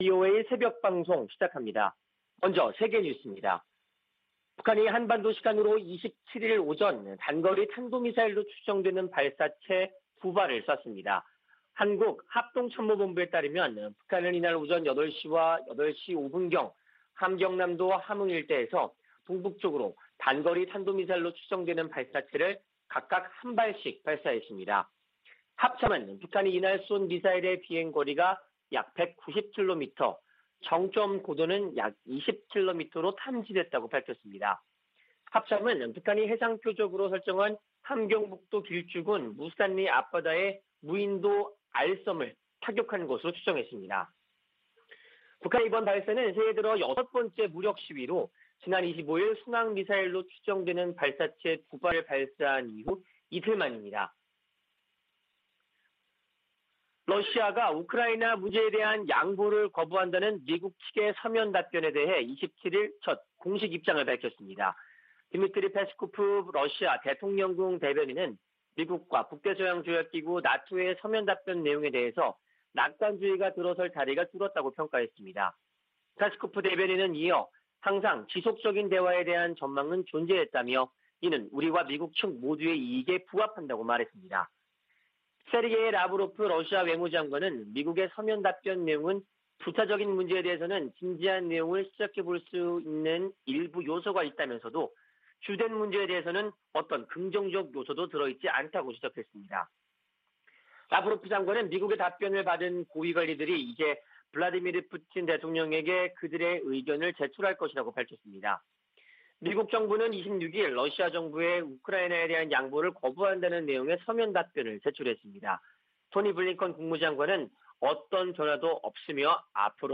VOA 한국어 '출발 뉴스 쇼', 2021년 1월 28일 방송입니다. 북한이 또 단거리 탄도미사일로 추정되는 발사체 2발을 동해상으로 쐈습니다.